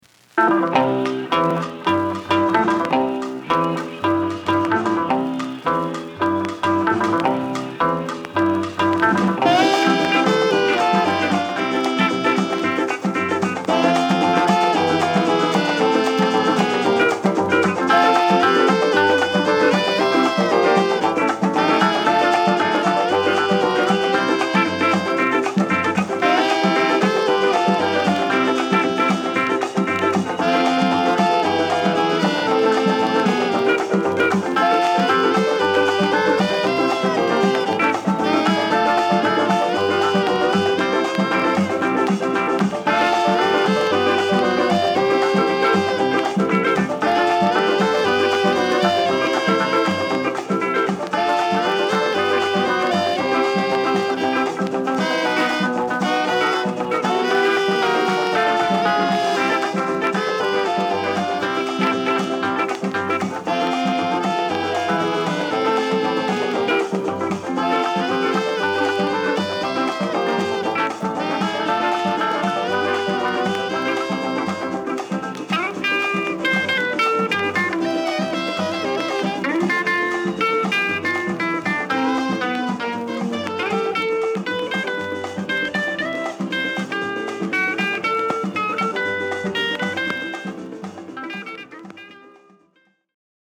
全編通してリラックスムード溢れるユルめの南国ブギーを連ねた好盤です。